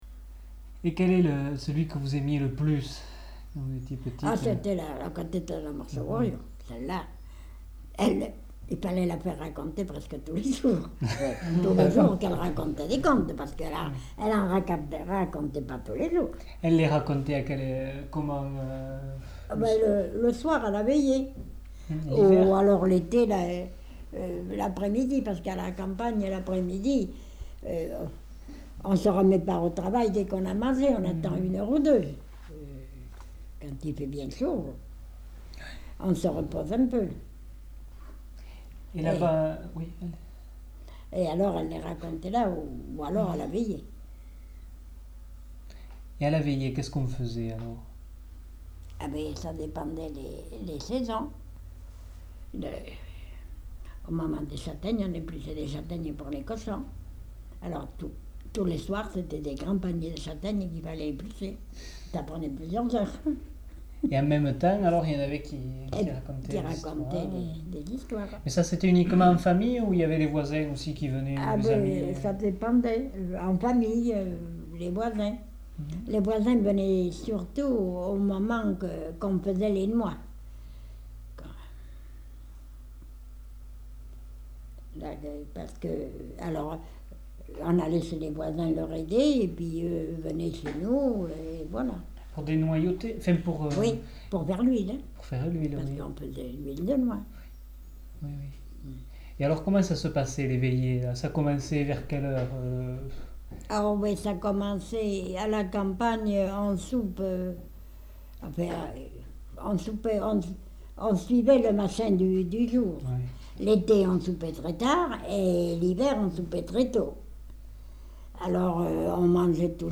Lieu : Tournay
Genre : témoignage thématique